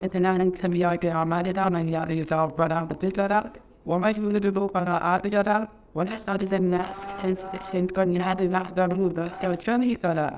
It is massively distorted (overloaded).
I also loaded the “less-distorted” clip in, and the AI tool cleaned it up much more, but may have introduced some new audio artifacts.
The “Sample-LessDistortion (enhanced).wav” is impressive considering the terrible quality of the original.